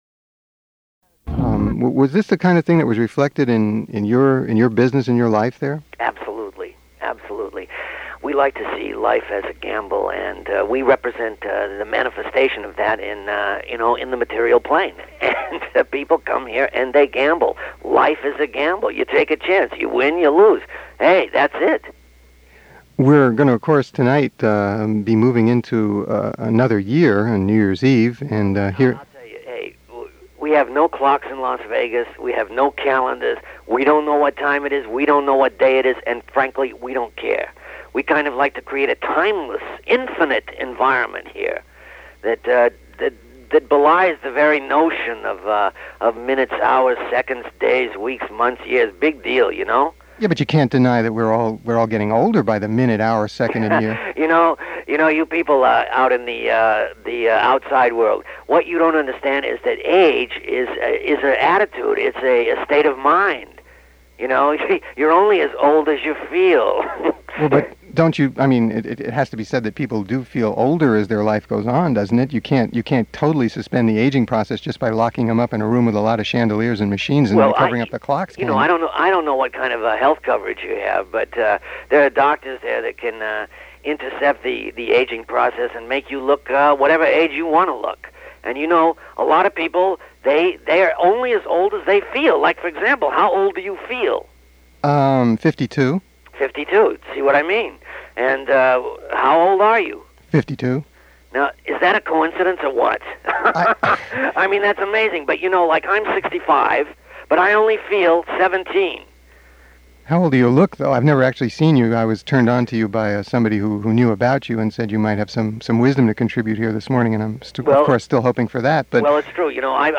Interview
A hilarious (fake?) interview with the mayor of Las Vegas